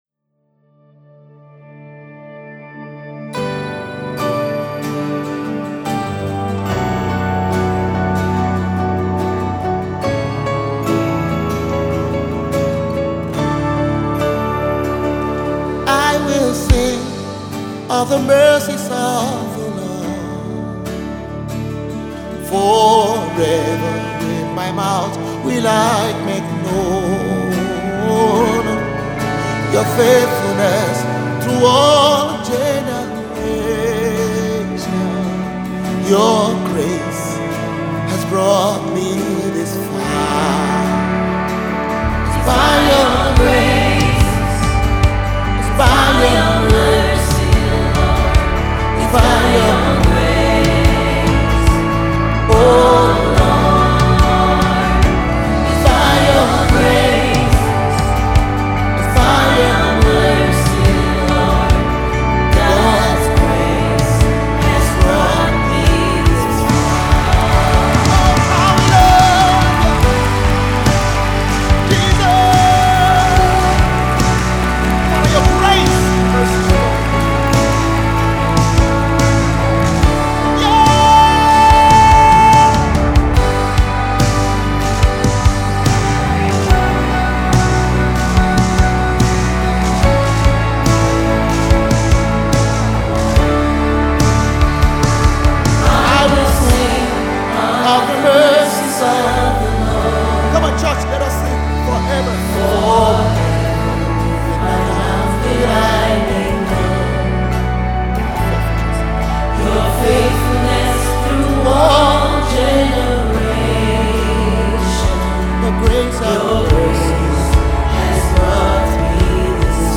Nigerian gospel music minister and prolific songwriter